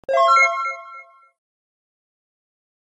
Play, download and share Win Soundd original sound button!!!!
win-soundd.mp3